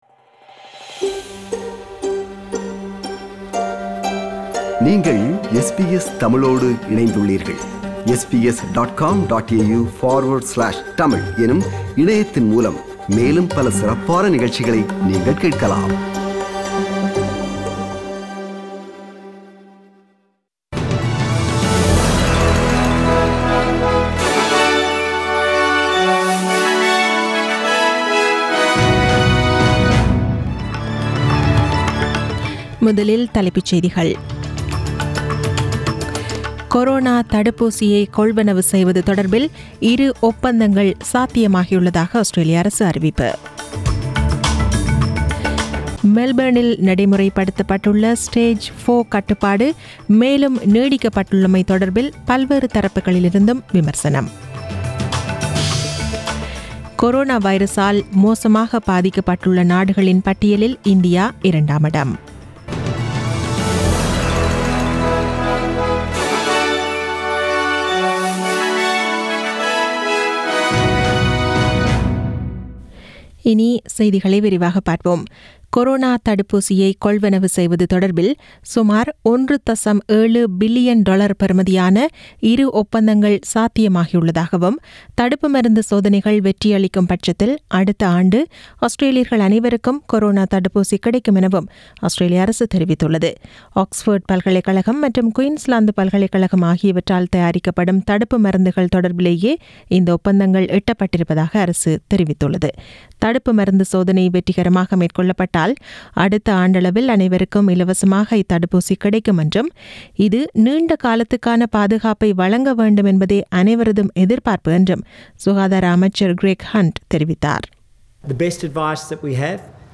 The news bulletin was aired on 7 September 2020 (Monday) at 8pm.